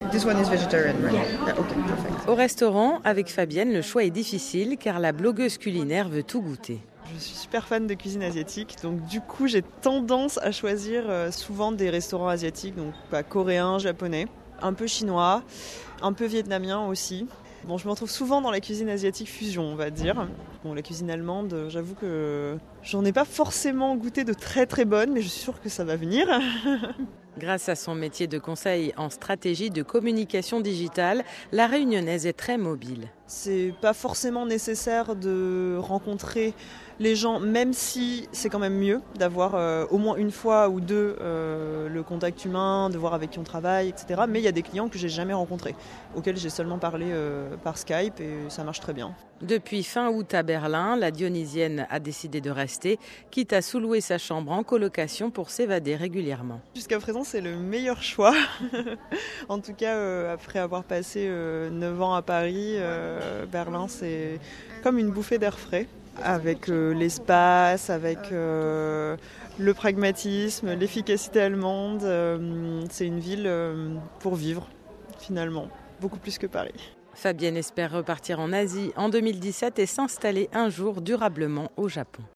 Reportage en Allemagne.